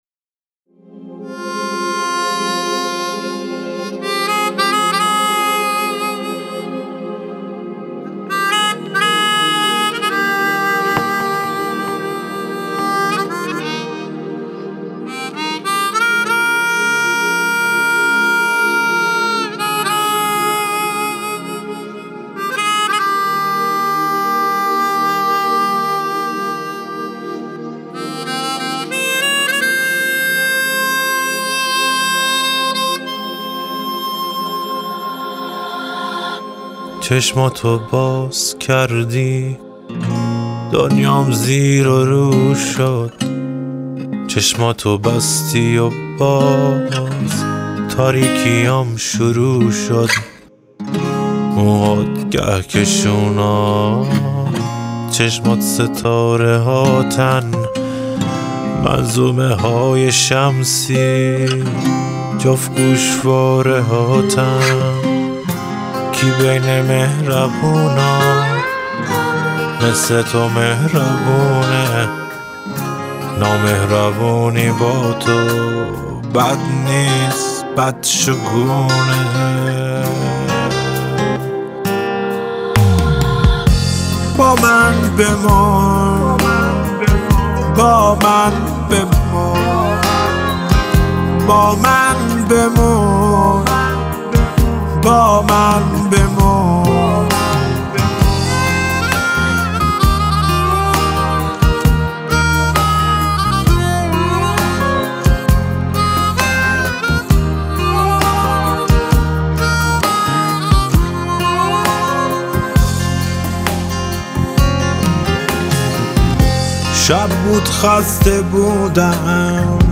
پاپ
آهنگ غمگین